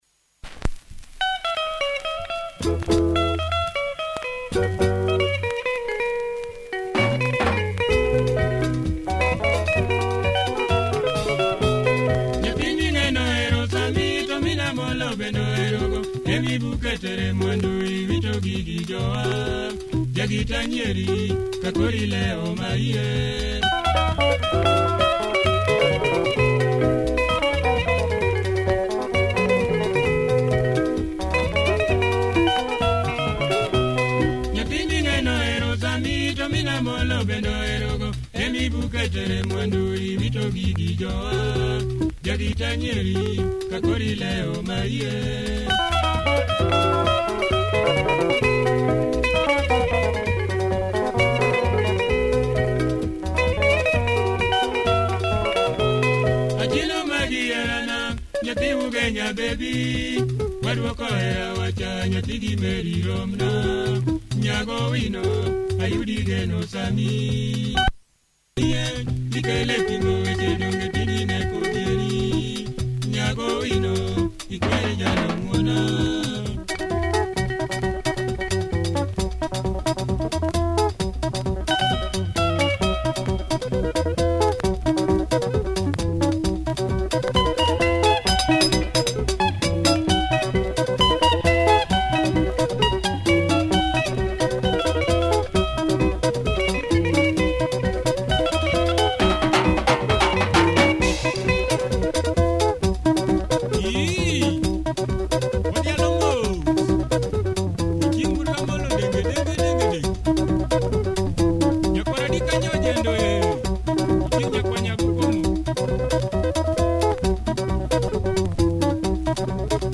Great LUO benga